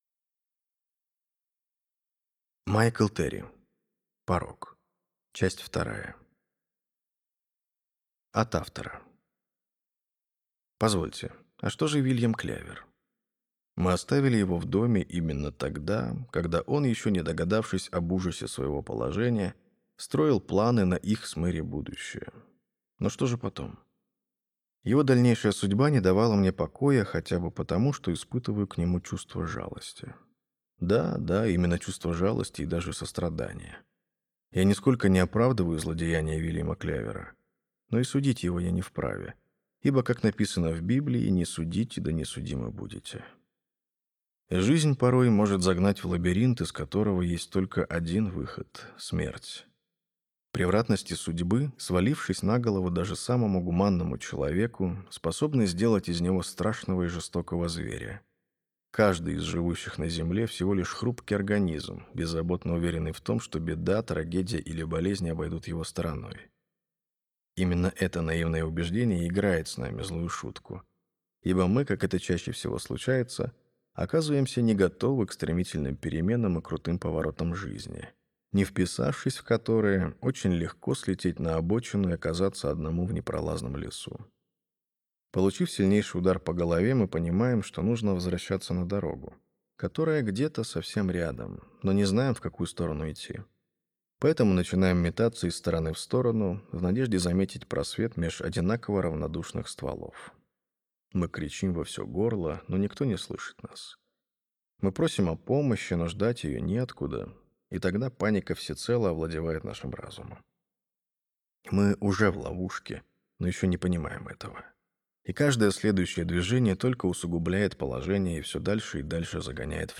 Аудиокнига Порог. Часть вторая | Библиотека аудиокниг